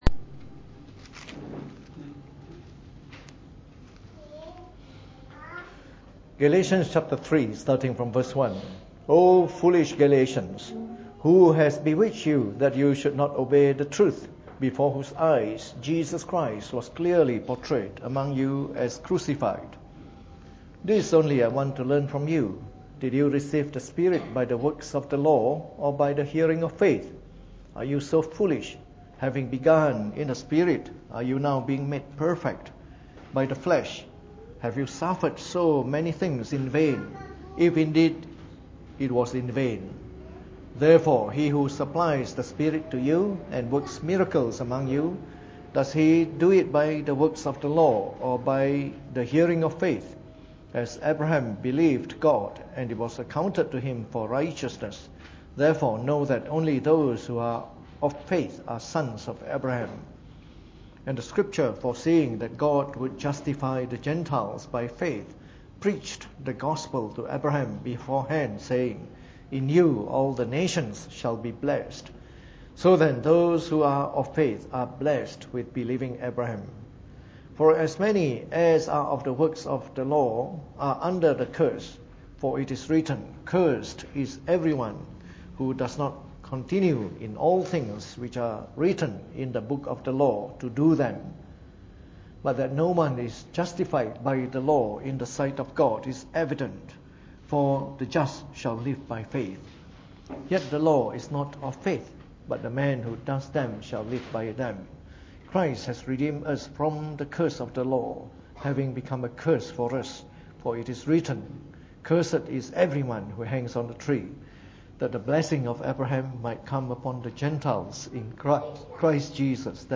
Preached on the 26th of October 2016 during the Bible Study, from our series on the Five Principles of the Reformed Faith.